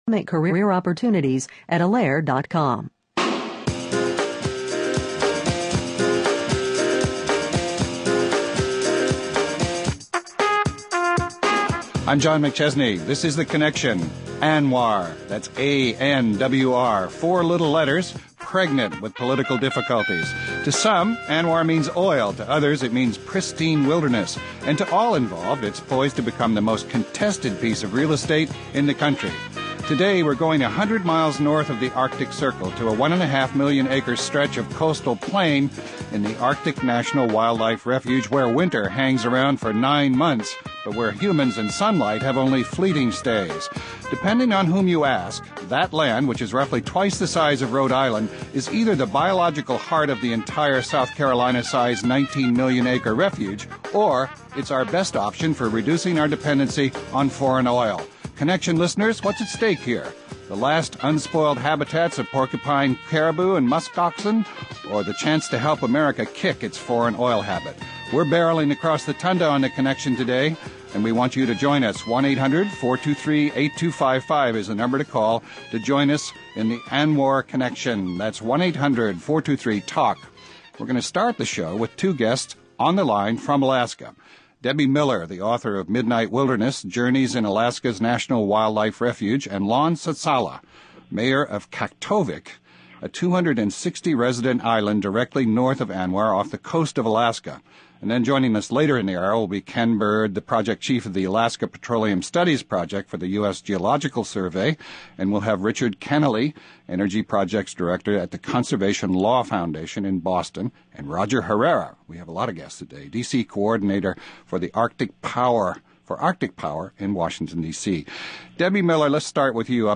We’re taking it to the tundra and exploring the truth about Anwar. (Hosted by Christopher Lydon)